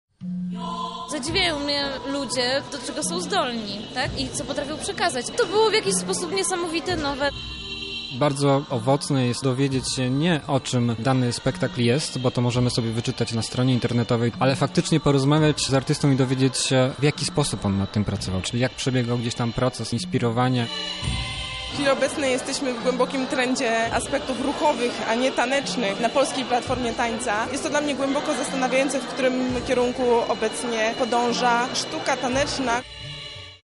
Oto jak widzowie ocenili poszczególne spektakle i festiwal tańca.